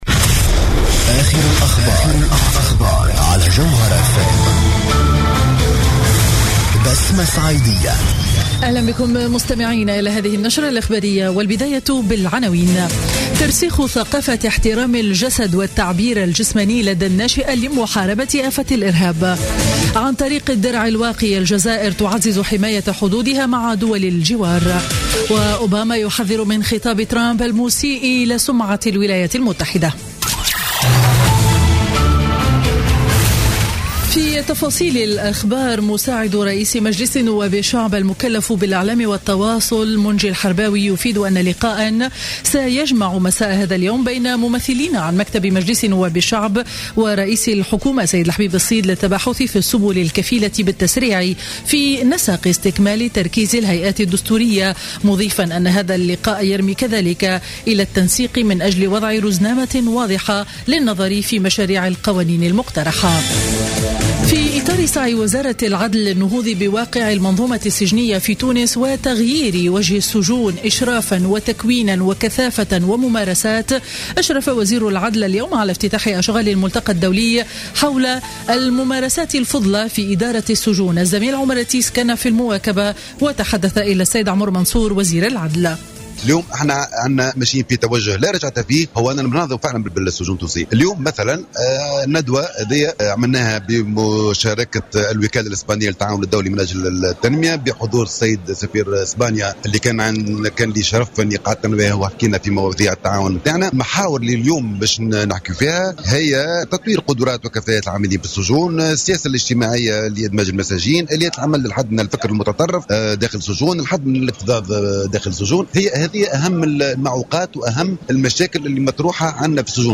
نشرة أخبار منتصف النهار ليوم الأربعاء 16 مارس 2016